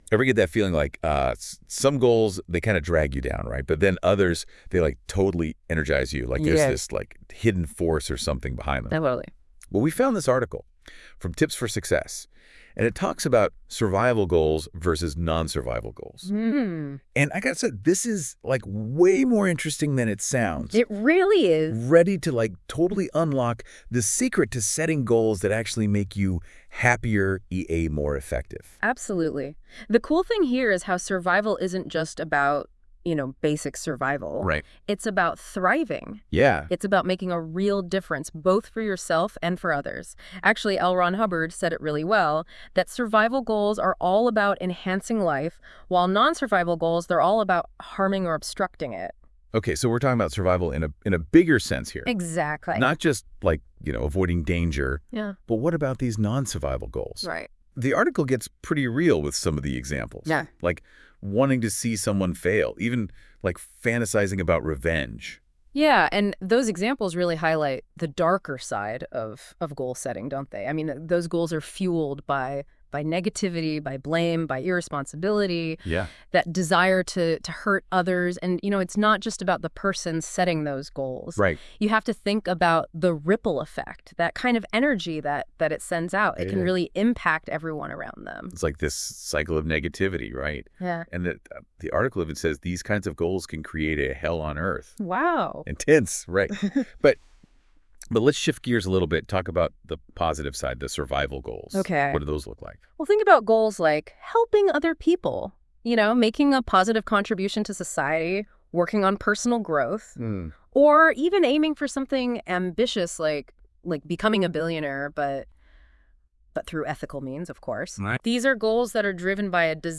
Check out this 7-minute podcast about the article (created by AI).